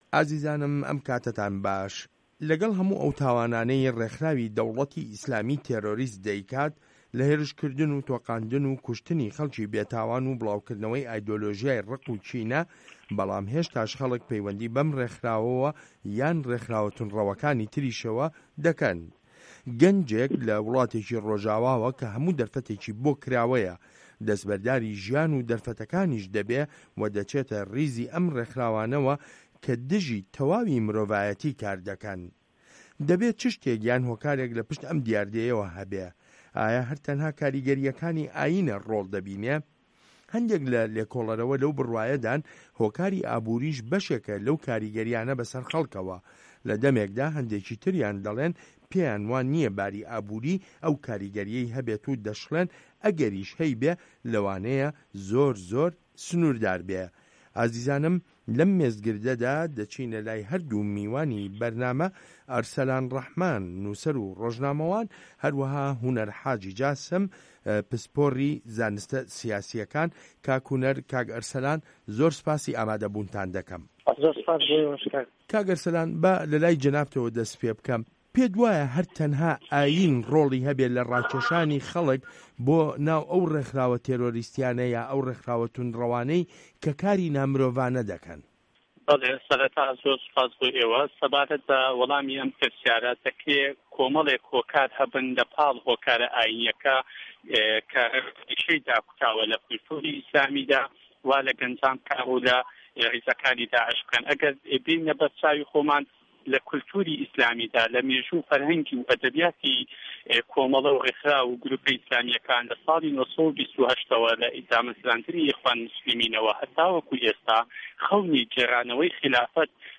مێزگرد: ده‌وڵه‌تی ئیسلامی و میکانیزمه‌کانی ڕاکێشانی خه‌ڵک